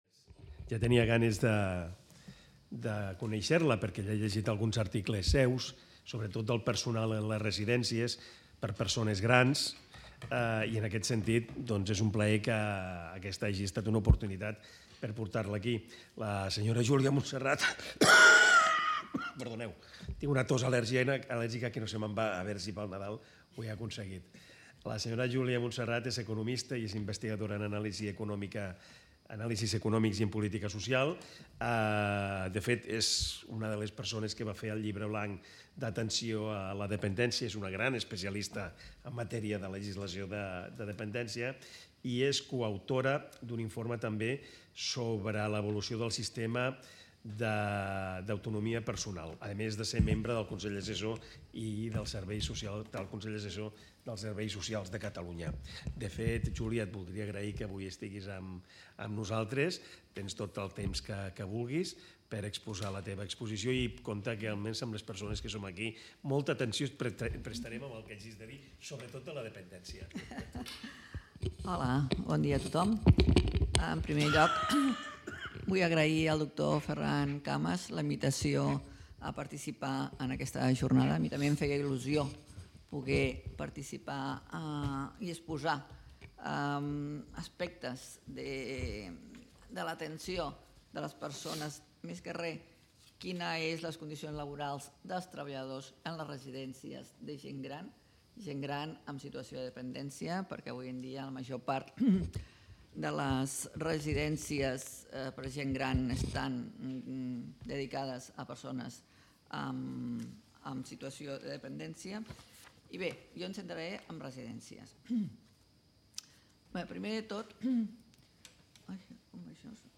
>  La ponent exposa la situació a Catalunya de les residències de gent gran i tota la xarxa assistencial, les característiques socioeconòmiques dels usuaris, condicions econòmiques i serveis a què es poden assolir, comparant-lo també amb la resta de les comunitats. La pedra angular de tota aquesta xarxa assistencial prové de la Llei de dependència, del 2006, i s’exposa la situació laboral dels treballadors en una situació força lamentable (ràtios per persona, remuneracions, etc.)